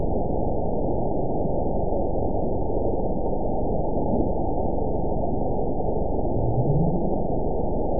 event 922340 date 12/30/24 time 01:33:37 GMT (5 months, 3 weeks ago) score 9.51 location TSS-AB02 detected by nrw target species NRW annotations +NRW Spectrogram: Frequency (kHz) vs. Time (s) audio not available .wav